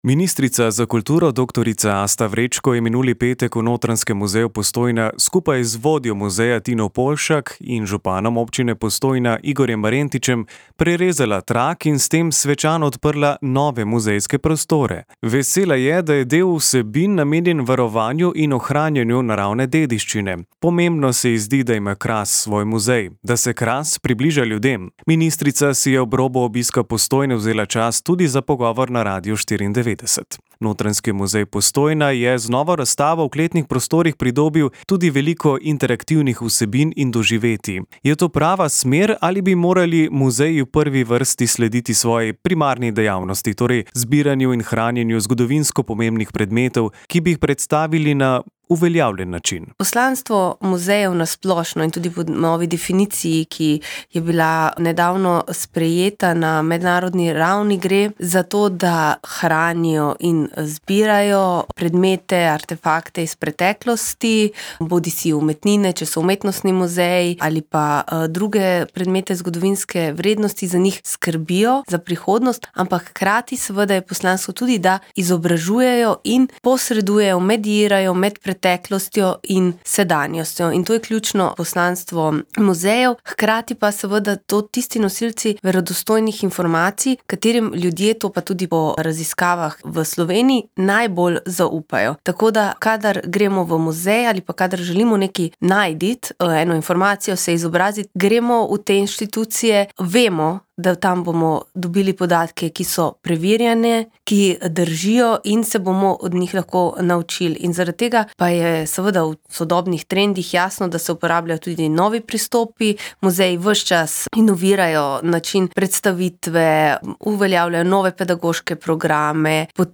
Minuli petek smo se pogovarjali z ministrico za kulturo dr. Asto Vrečko, ki je Postojno obiskala v okviru odprtja novih razstavnih prostorov Notranjskega muzeja Postojna.
ku25-asta-vrecko-na-odprtju-muzeja-komplet.mp3